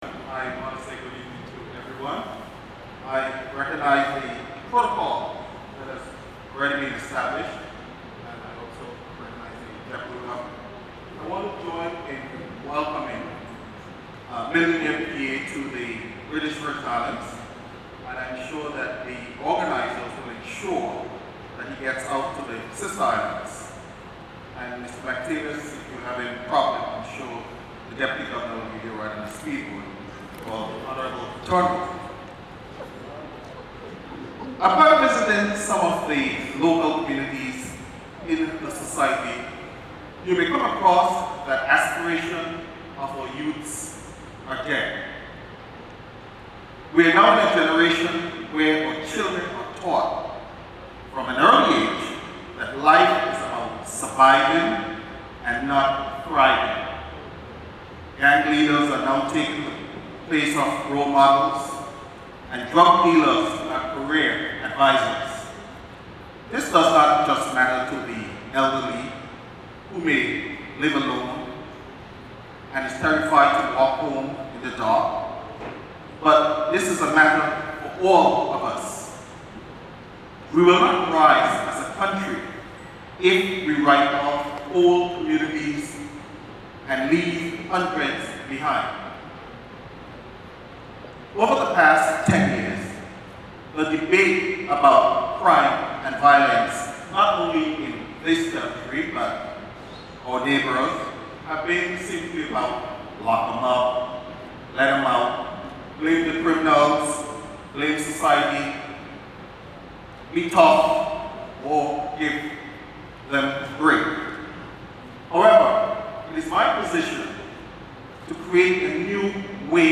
Mr Willock, in his short but comprehensive presentation, said stopping violence is like a four legged chair.
Other speakers at the Stop the Violence event organised by the Dream Big Group were Leader of the Opposition Hon Andrew A. Fahie (R1), Hon Melvin M. Turnbull (R2) and Deputy Governor David D. Archer Jr.